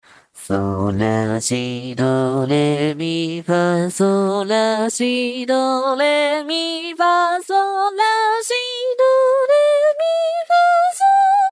藤咲透明_JPVCV_爽やか（kire）推薦            DL
収録音階：E3 G3 B3 D4 E4 G4(裏声)